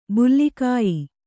♪ bulli kāyi